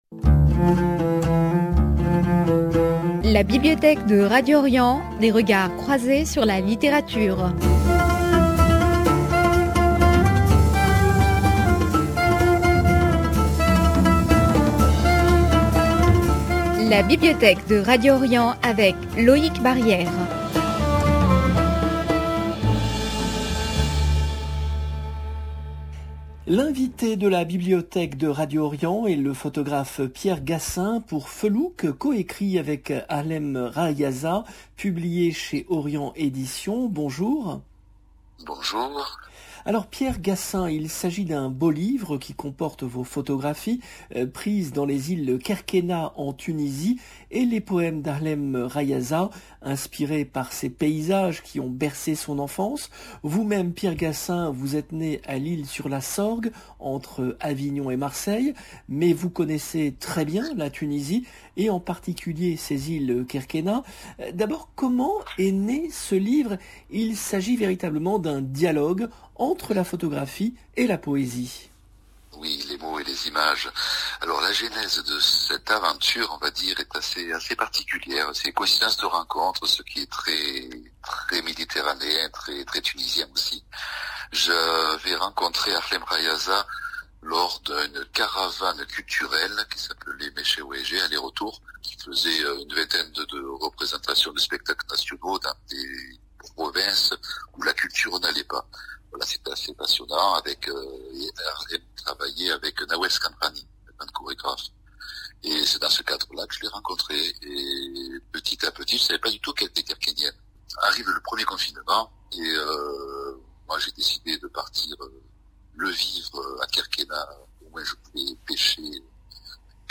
Un livre qui évoque notamment la charfiya, ce système de pêche traditionnel propre aux îles Kerkennah, inscrit au patrimoine immatériel de l’UNESCO en 2020 Emission diffusée le samedi 19 février 2022